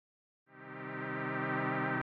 Boogz Riser FX.wav